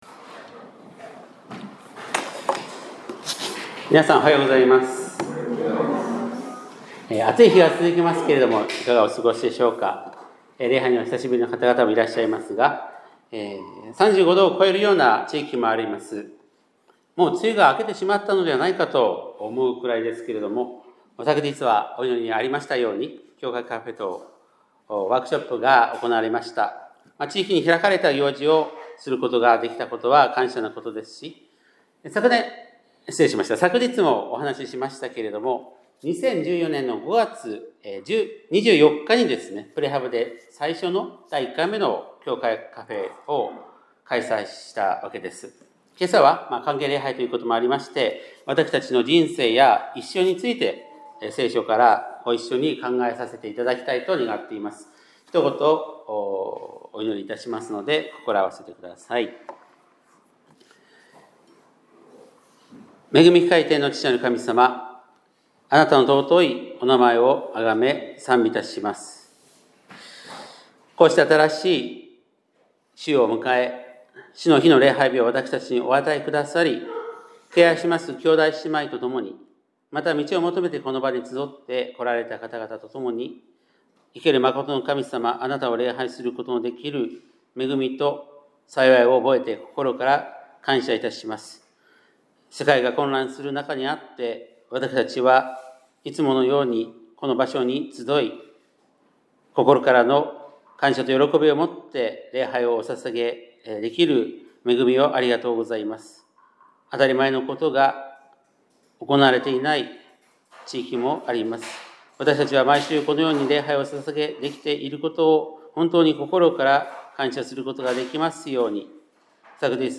2025年6月22日（日）礼拝メッセージ